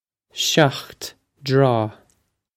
Pronunciation for how to say
shokht draw
This is an approximate phonetic pronunciation of the phrase.